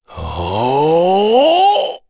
Dakaris-Vox_Casting1_kr.wav